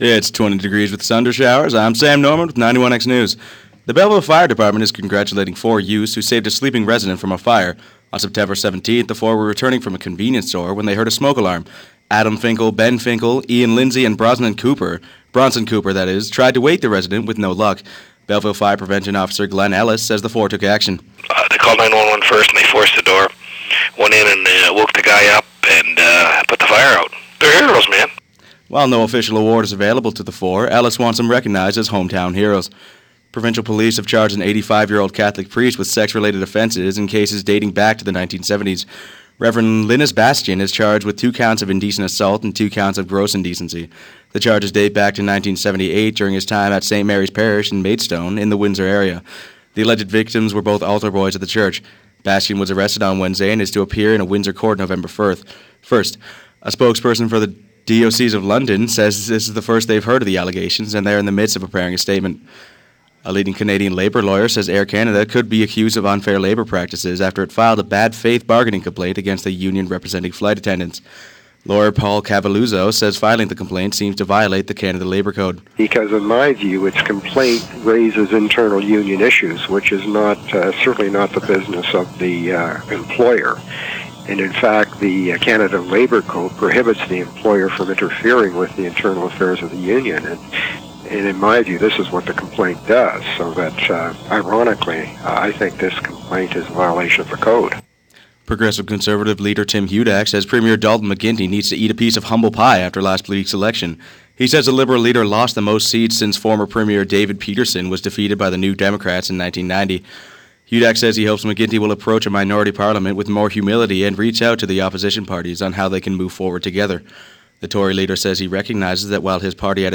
91x-news-oct.-14-2-pm.mp3